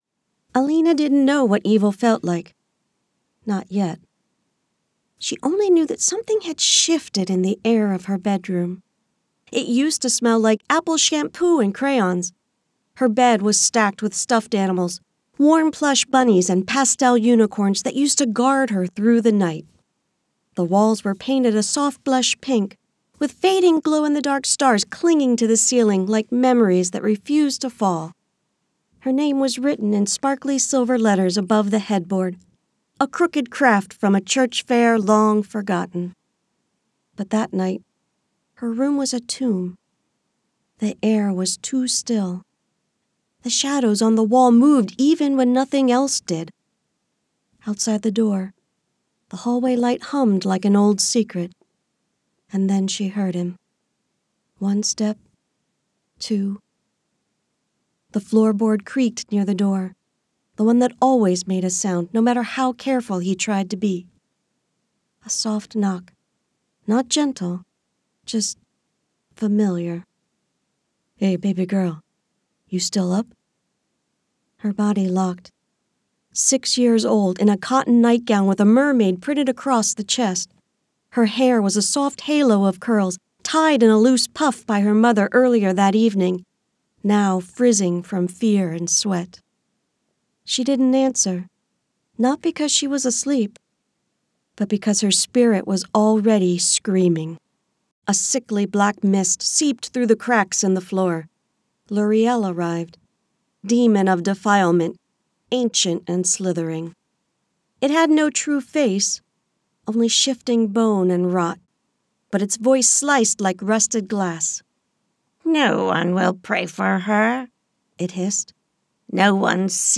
1106Narrative_Sample_-_The_First_Trail.mp3